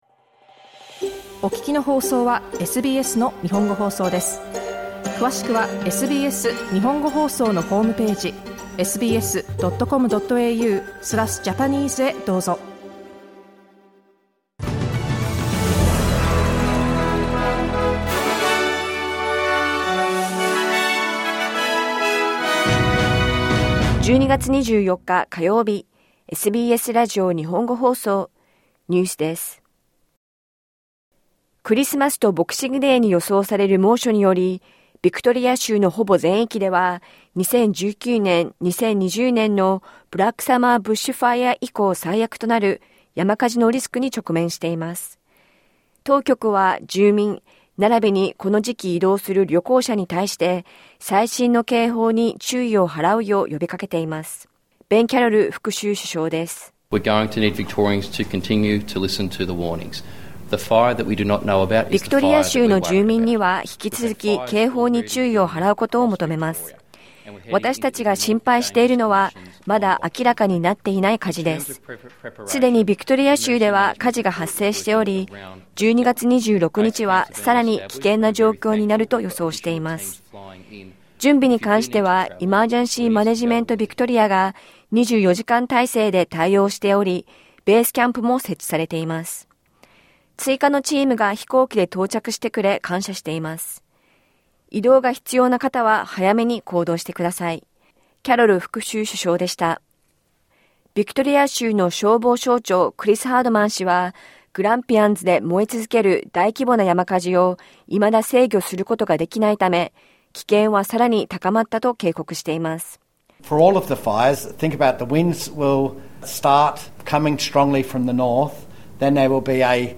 クリスマスとボクシングデーに予想される猛暑により、ビクトリア州のほぼ全域では山火事のリスクが高まっており、当局は住民に対して、最新の警報に注意を払うよう呼びかけています。午後１時から放送されたラジオ番組のニュース部分をお届けします。